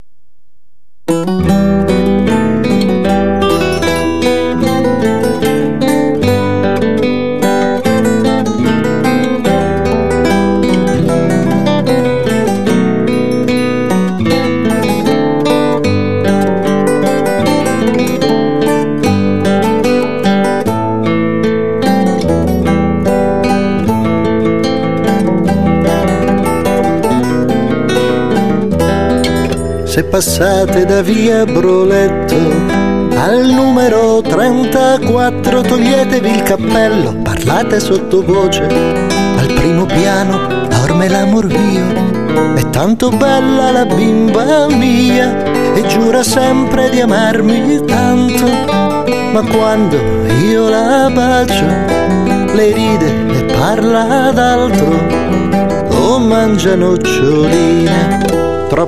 una ballata che ha solo...43 anni.